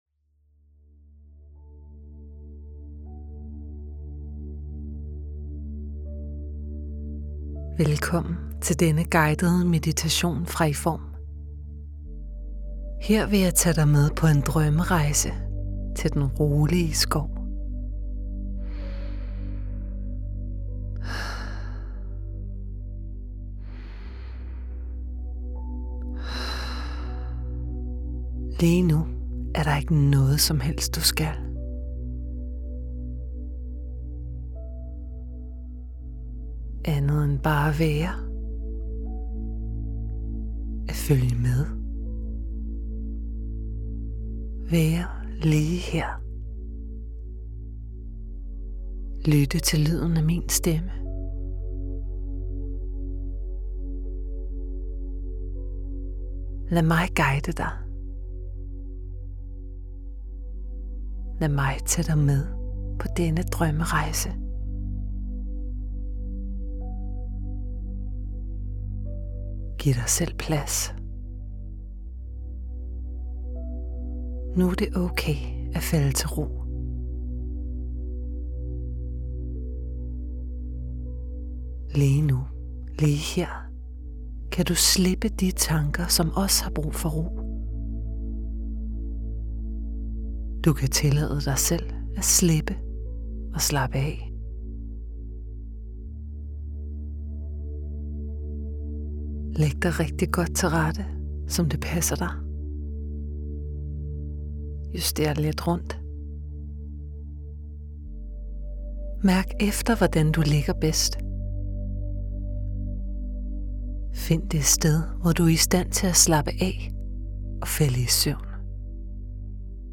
De sidste fem minutter indeholder ingen speak, men er blot en fortsættelse af det rolige lydspor.